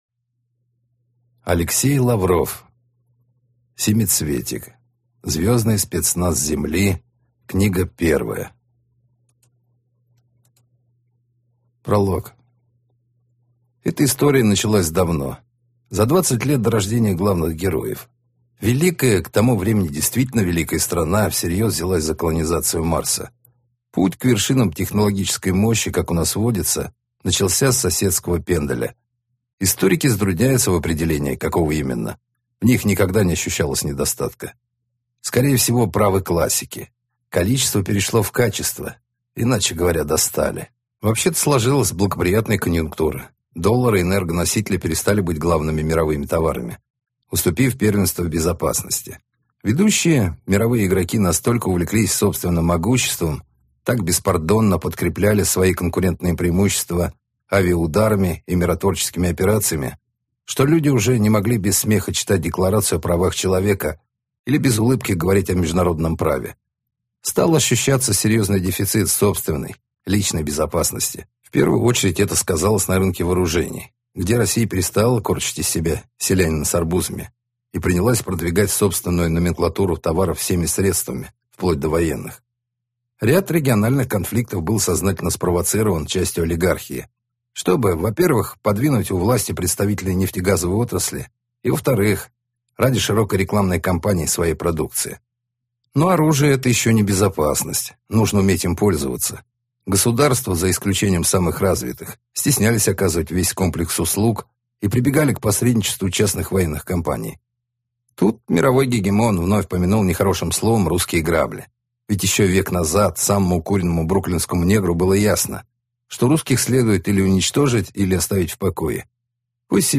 Аудиокнига Семицветик. Книга1. Звёздный спецназ Земли | Библиотека аудиокниг